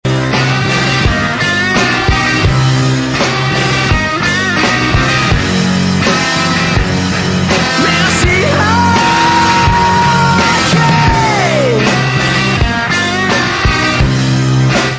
pop indie